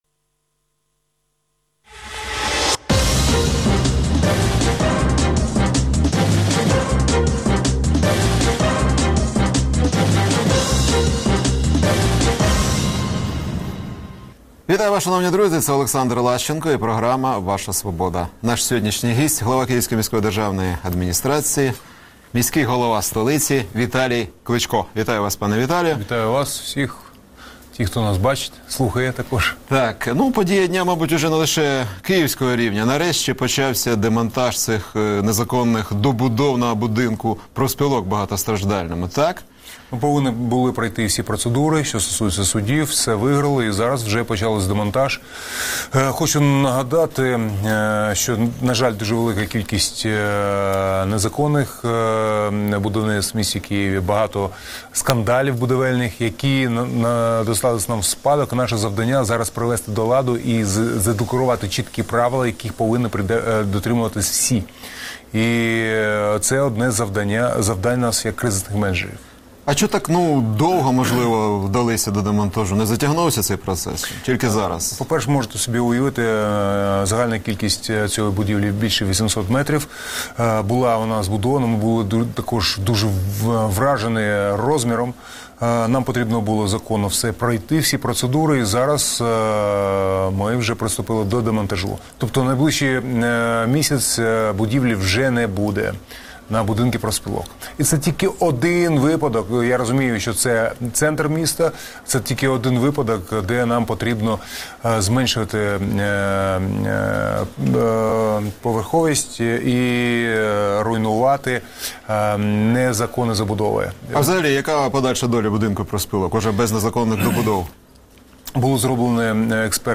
Ваша Свобода | Інтерв’ю з Київським міським головою Віталієм Кличком